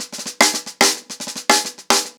TheQuest-110BPM.29.wav